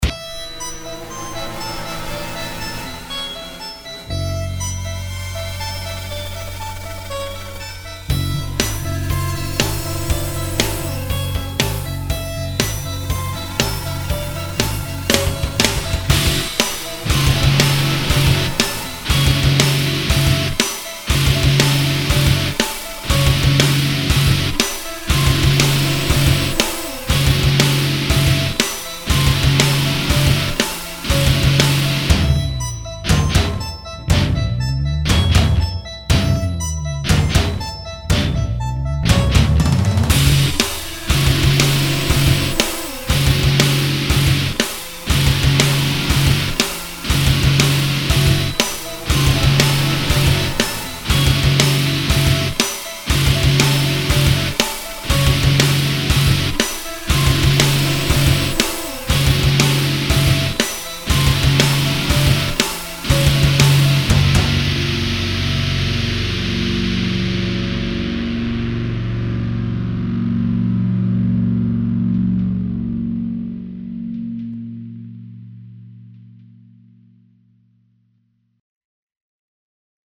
Guitar Driven
Hard Rock/Cinematic/Electronic